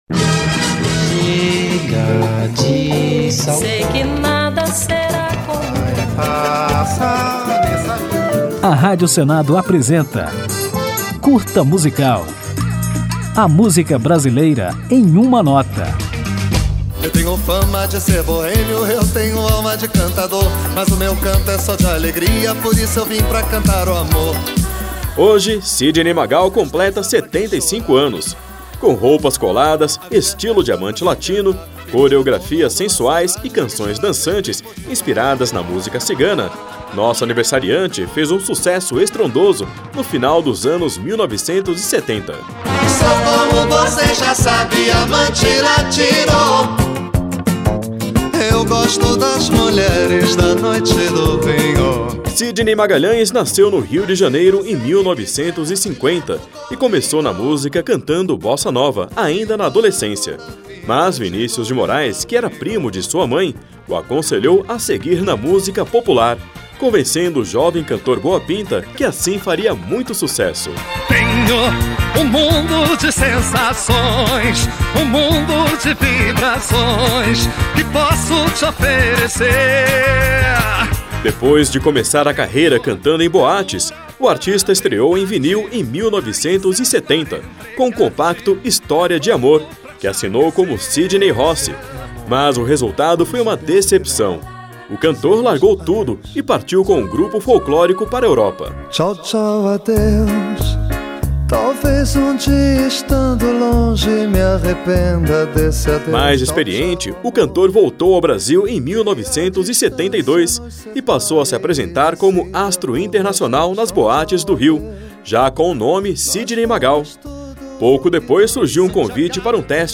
Nesta homenagem que o Curta Musical preparou, você vai conferir a história de Sidney Magal e ainda vai ouvi-lo no sucesso Sandra Rosa Madalena "A Cigana".